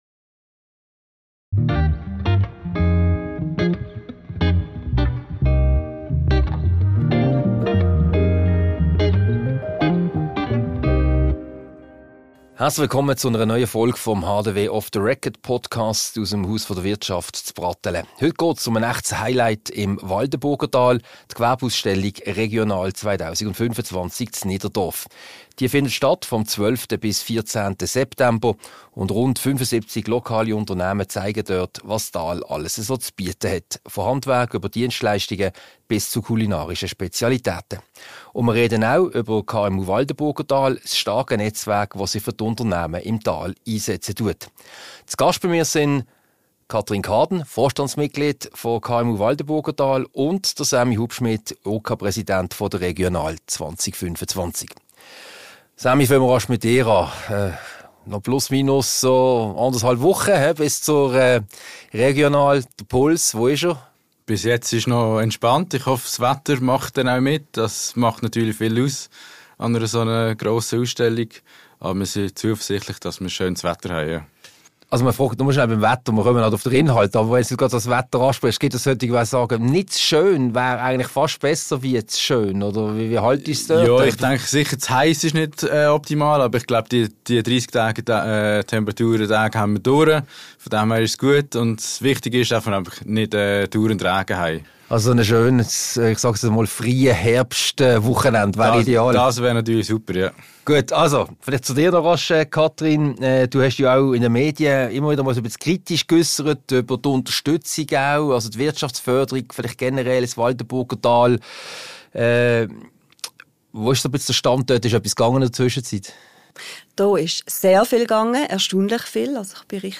Diese Podcast-Ausgabe wurde im Multimedia-Studio vom Haus der Wirtschaft HDW aufgezeichnet.